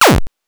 8 bits Elements / laser shot
laser_shot_4.wav